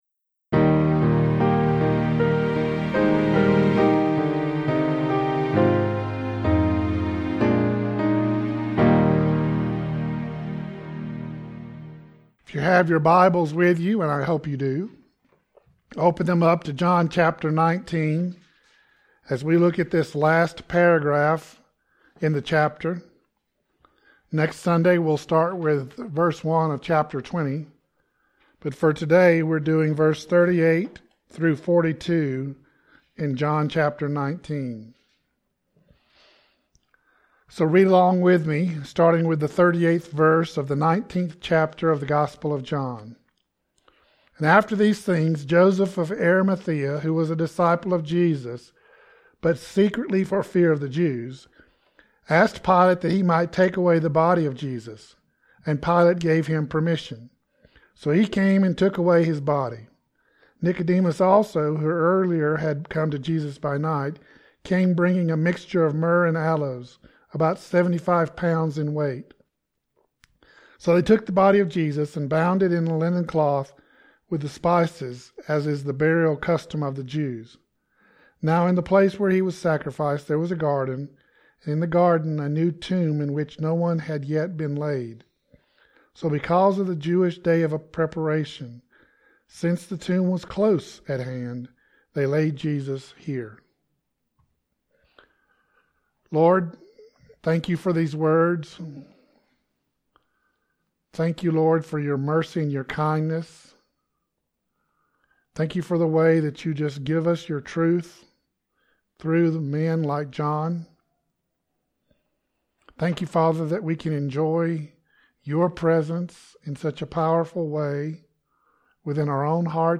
The latest sermon & selected archives from Castle Rock Baptist Church, Castle Rock, Colorado.